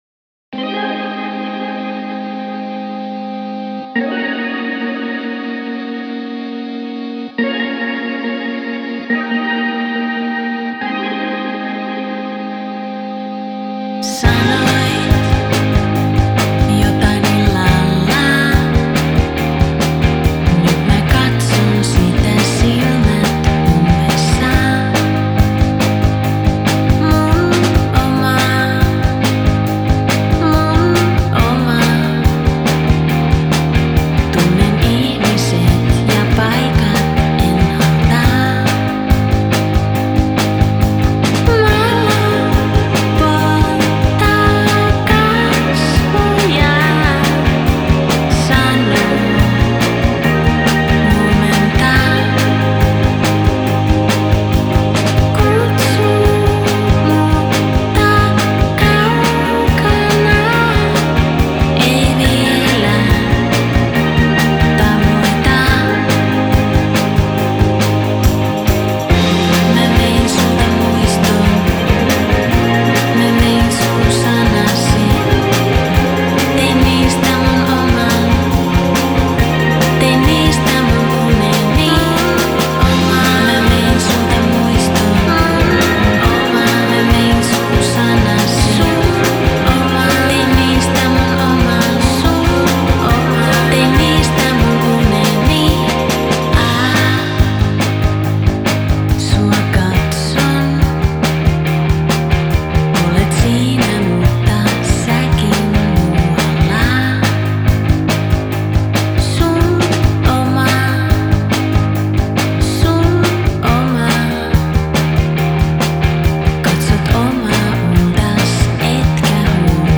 вокал
перкуссия
Style: Dream Pop